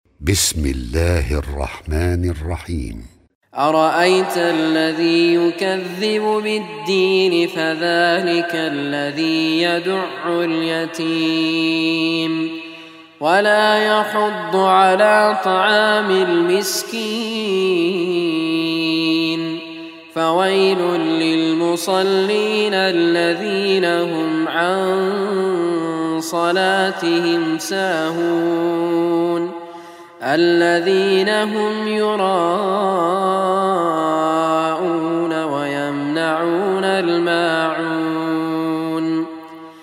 quran recitation